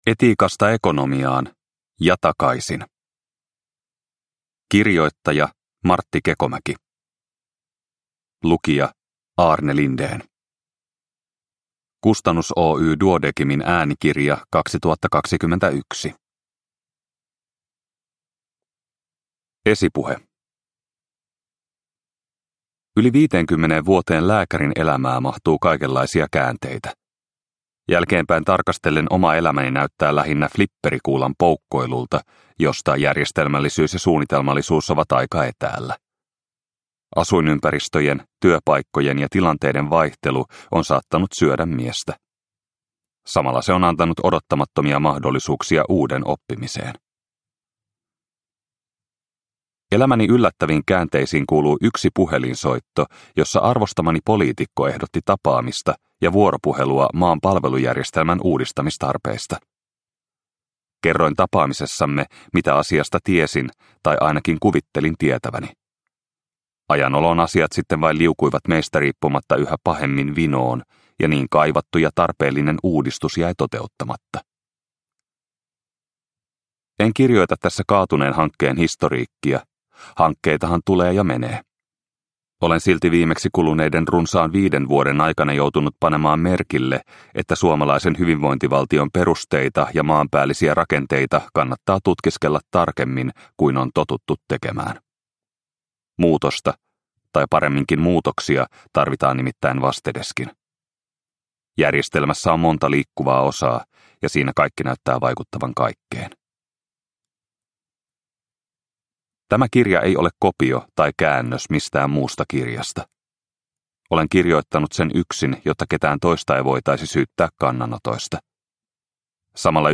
Etiikasta ekonomiaan - ja takaisin – Ljudbok – Laddas ner